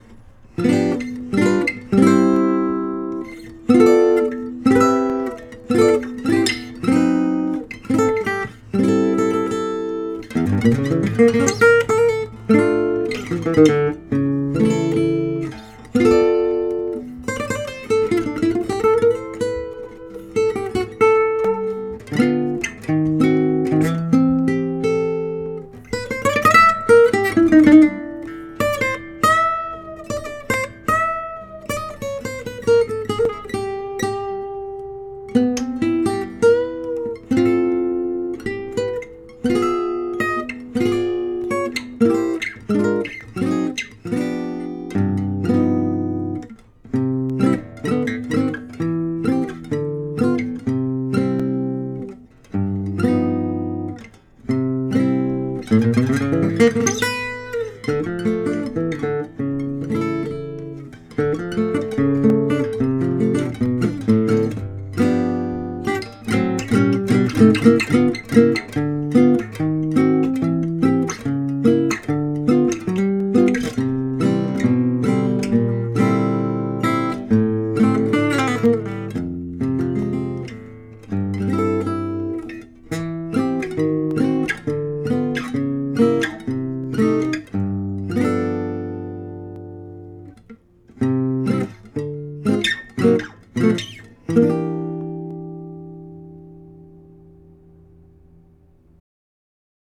There's a section in it where as it is I find it very awkward to play the melody over, I think I know which chord I'm rushing. Plus there are some pops is this recording, no idea why and where from, so I need to record this again anyway.